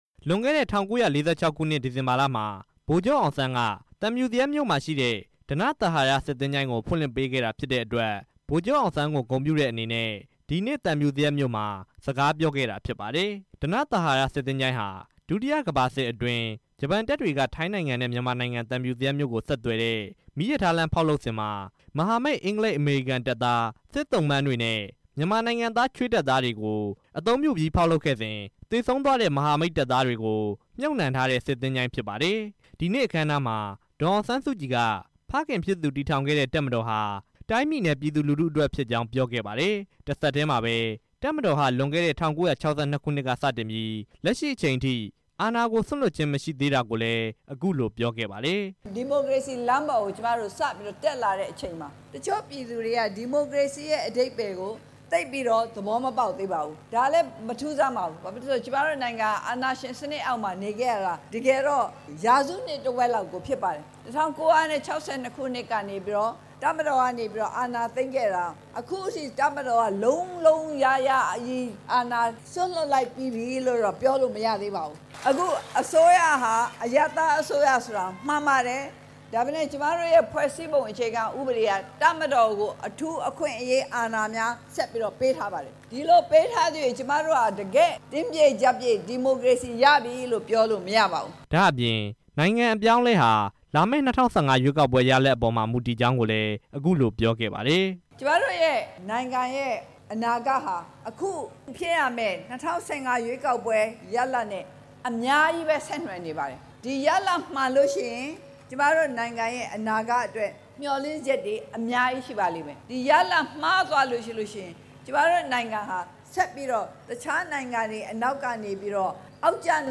ဒီကနေ့ မွန်ပြည်နယ် သံဖြူဇရပ်မြို့ ကြာကန်ဘောလုံး ကွင်း လူထုဟောပြောပွဲမှာ တက်ရောက်လာတဲ့ ပရိသတ်တစ်ဦးက ၅၉(စ) ကိုပြင်လို့မရခဲ့ ရင် ပြည်ထောင်စုဝန်ကြီးချုပ်ဆိုပြီး ရာထူးအသစ်နဲ့ ကမ်းလှမ်းခဲ့ရင် လက်ခံမလားလို့ မေးမြန်းရာ မှာ ဒေါ်အောင်ဆန်းစုကြည်က အဲဒီလို ဖြေကြား ခဲ့တာ ဖြစ်ပါတယ်။
ဒီကနေ့ သံဖြူဇရပ် လူထုဟော ပြောပွဲကို ဒေသခံ လူဦးရေ ၄ ထောင်ကျော် တက်ရောက်ခဲ့ကြပြီး ဒေါ်အောင်ဆန်း စုကြည်ကို မေးခွန်းတွေ မေးမြန်းခဲ့ကြပါတယ်။